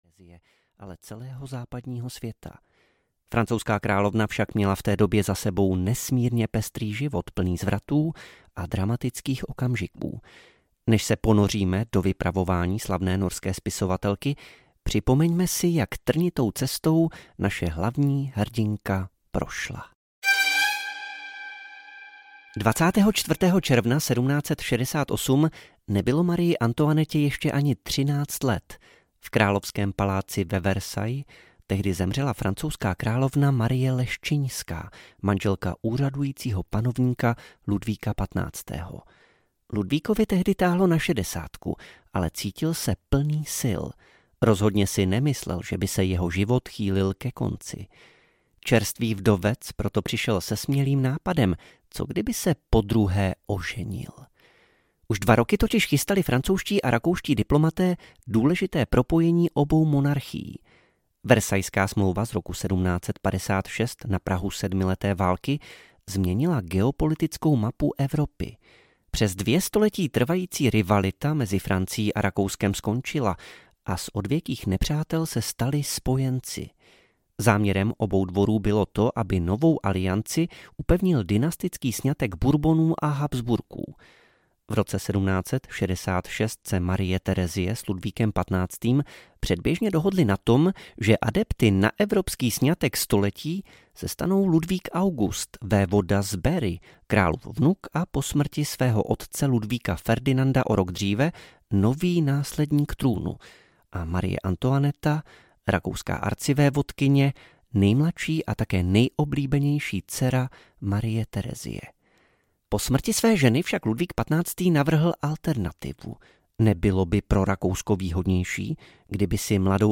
Ukázka z knihy
marie-antoinetta-a-revoluce-audiokniha